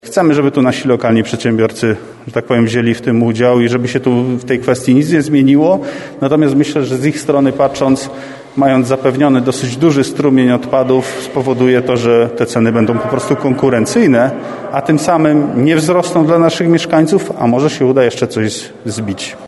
Pięć samorządów powiatu dębickiego ogłosiło wspólny przetarg na zagospodarowanie odpadów komunalnych. Chcą w ten sposób zawalczyć o niższe ceny dla mieszkańców. Do wspólnego porozumienia przystąpiło miasto Dębica oraz gminy Brzostek, Jodłowa, Żyraków i Pilzno, mówi Jerzy Sieradzki pełniący obowiązki burmistrza Dębicy.